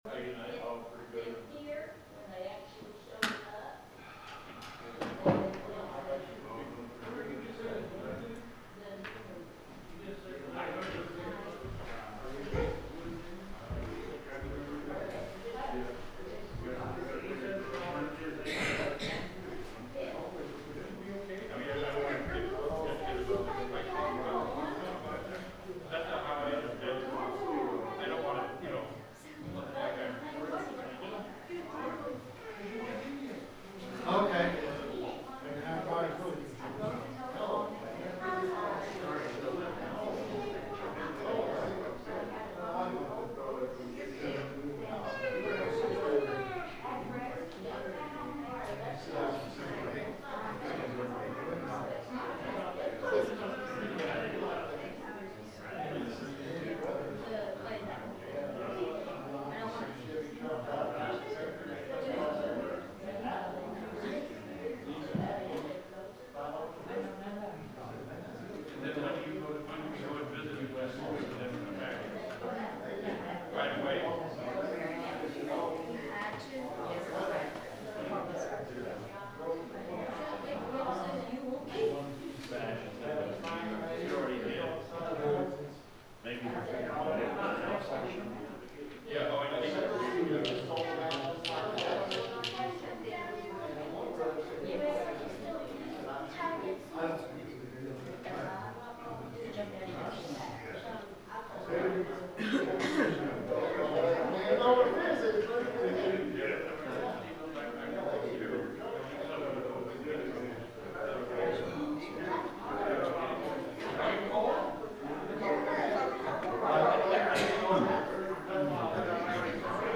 The sermon is from our live stream on 2/11/2026